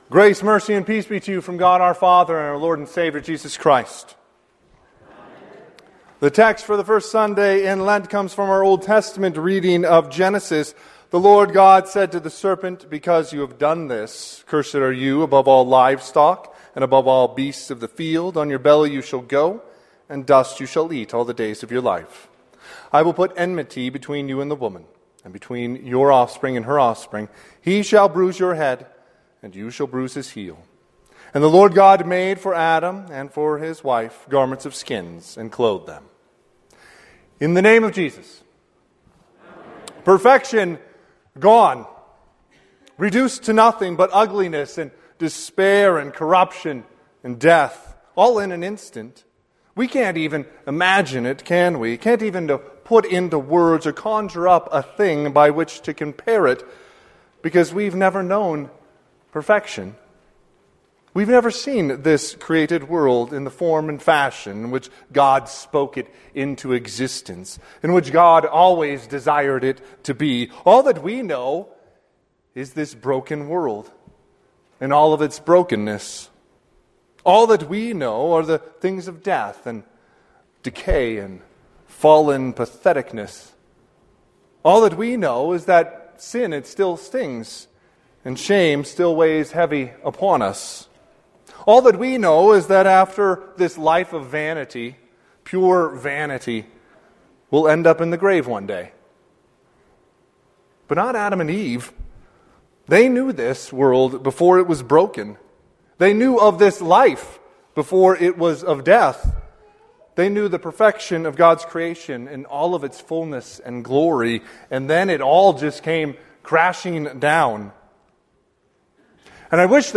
Sermon - 2/22/2026 - Wheat Ridge Evangelical Lutheran Church, Wheat Ridge, Colorado
First Sunday in Lent